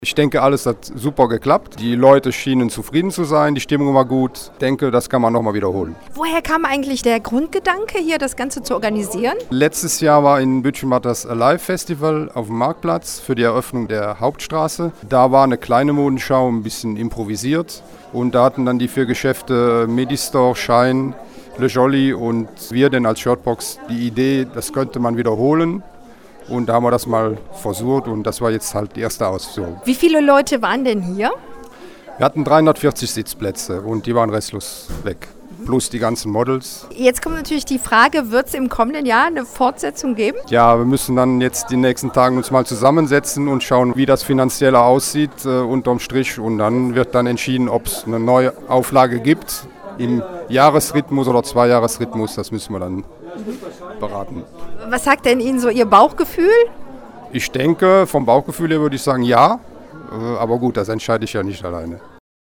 Am Samstag drehte sich im vollbesetzen Mozartsaal in Worriken alles um das Thema „Die aktuellen Modetrends für den Herbst und den Winter“.
Besucher und Veranstalter zeigten sich im Gespräch